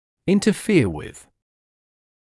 [ˌɪntə’fɪə wɪð] [ˌинтэ’фиэ уиз] мешать (чему-то), служить помехой, нарушать какой-то процесс